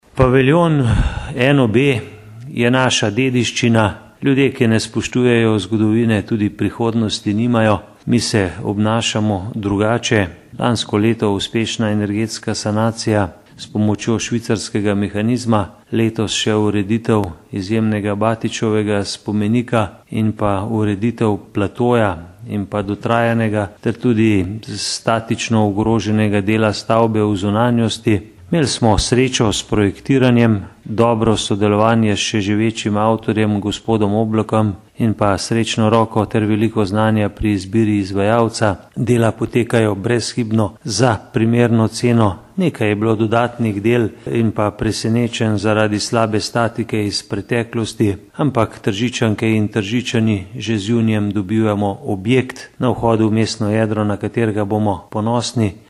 63343_izjavazupanaobcinetrzicmag.borutasajovicaozakljuckuprenovepaviljonanob.mp3